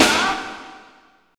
49.07 SNR.wav